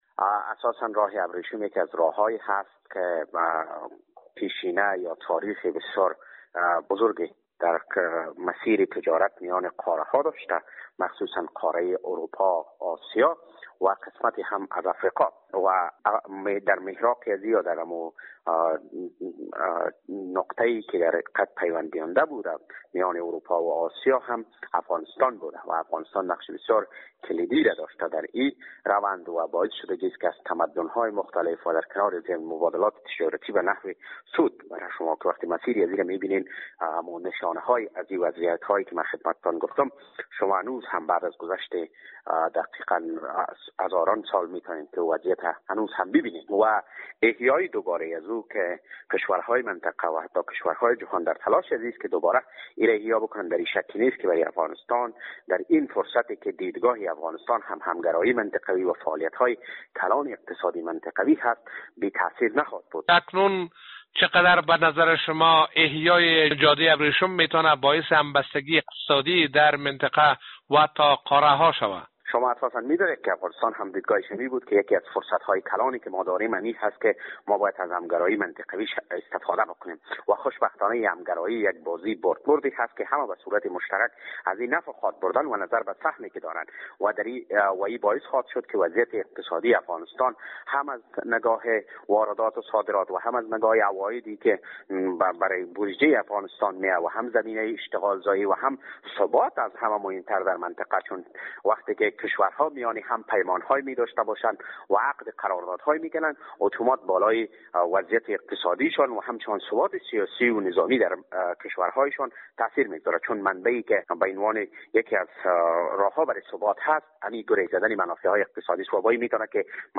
در گفت و گو با خبرنگار رادیو دری